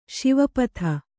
♪ Śiva patha